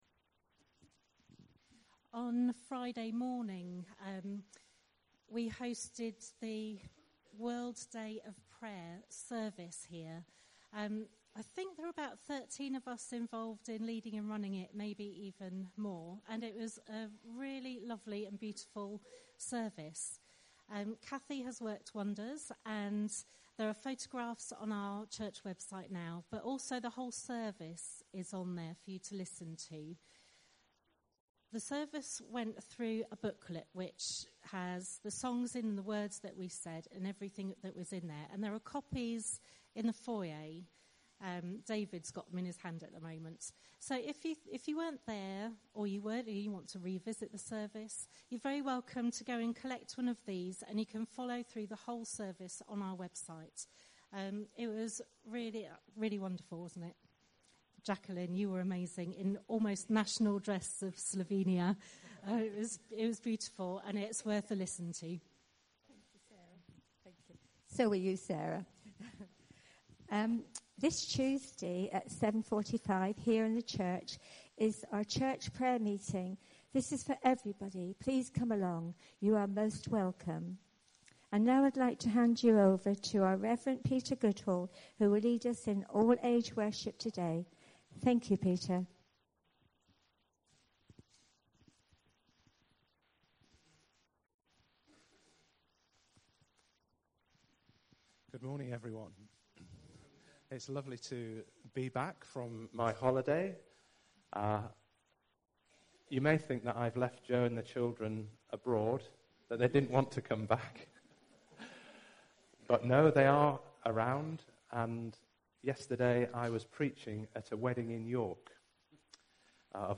Service Type: All Age Worship